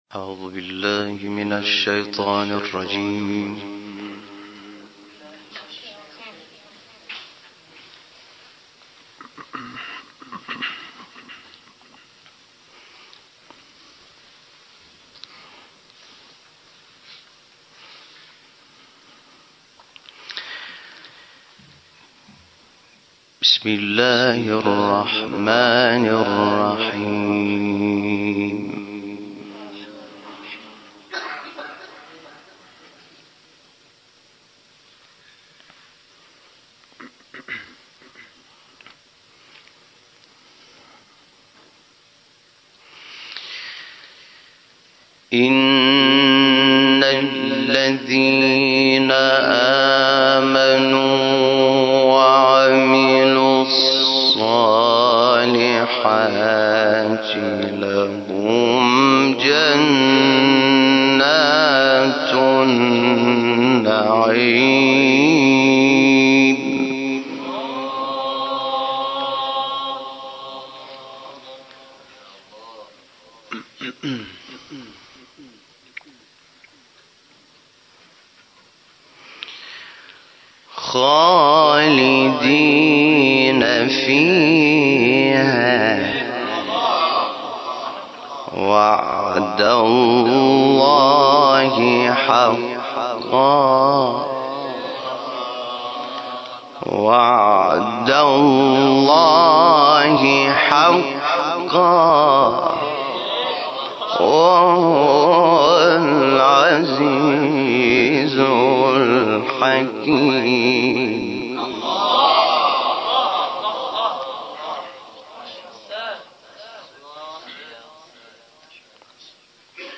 گروه شبکه اجتماعی: حامد شاکرنژاد شب‌های دهه سوم ماه صفر را در حسینیه بنی الزهراء(س) تهران به تلاوت آیاتی از کلام‌الله مجید می‌پردازد.